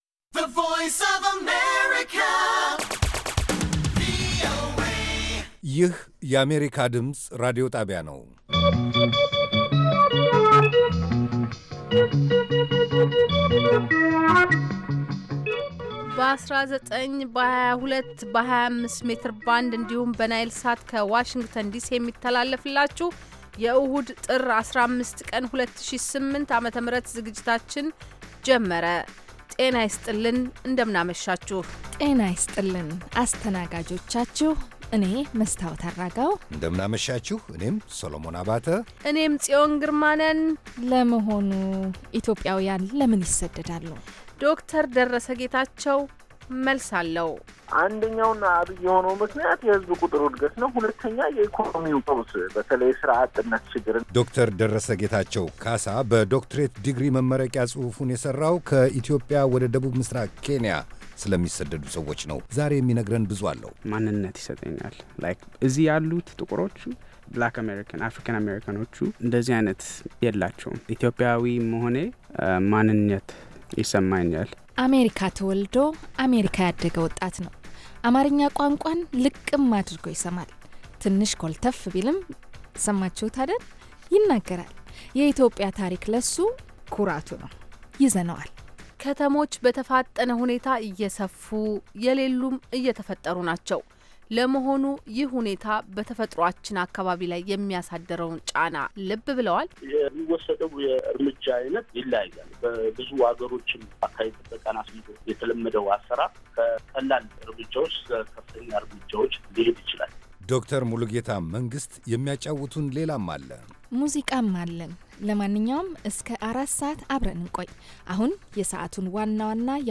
ቪኦኤ በየዕለቱ ከምሽቱ 3 ሰዓት በኢትዮጵያ ኣቆጣጠር ጀምሮ በአማርኛ፣ በአጭር ሞገድ 22፣ 25 እና 31 ሜትር ባንድ የ60 ደቂቃ ሥርጭቱ ዜና፣ አበይት ዜናዎች ትንታኔና ሌሎችም ወቅታዊ መረጃዎችን የያዙ ፕሮግራሞች ያስተላልፋል። ዕሁድ፡- ራዲዮ መፅሔት፣ መስተዋት (የወጣቶች ፕሮግራም) - ሁለቱ ዝግጅቶች በየሣምንቱ ይፈራረቃሉ፡፡